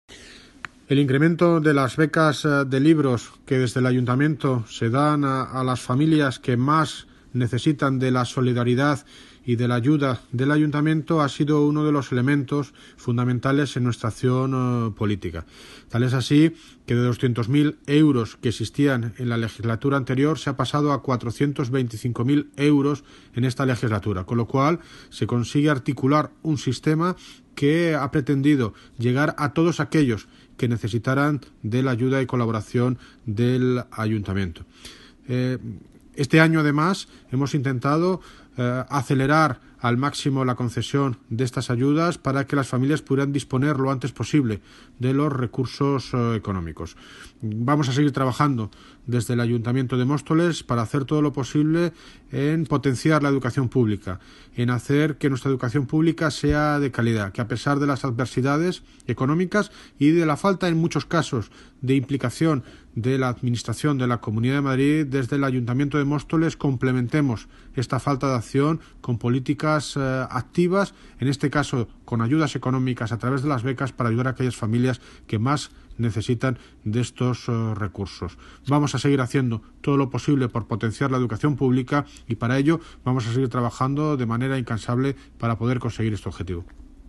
Audio - David Lucas (Alcalde de Móstoles) Sobre becas